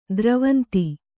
♪ dravanti